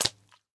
Minecraft Version Minecraft Version snapshot Latest Release | Latest Snapshot snapshot / assets / minecraft / sounds / mob / pufferfish / flop4.ogg Compare With Compare With Latest Release | Latest Snapshot
flop4.ogg